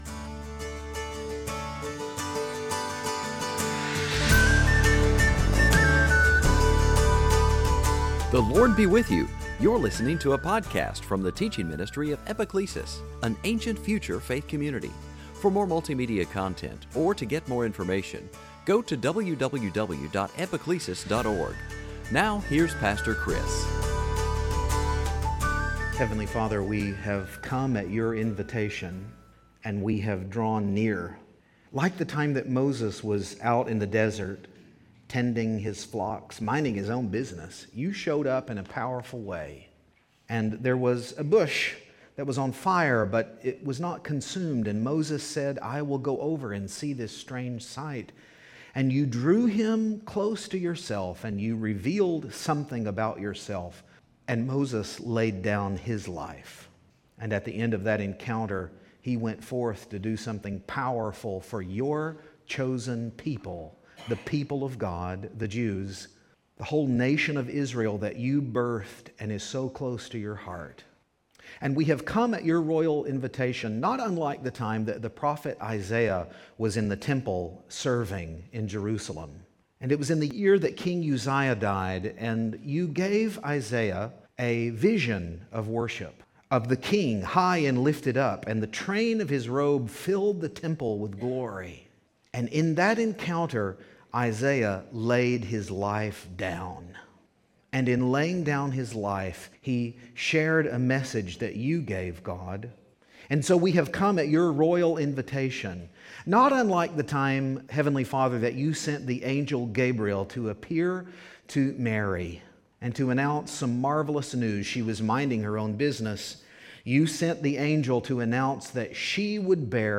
2016 Sunday Teaching big story Easter Parable salvation history son vineyard owner Easter Sunday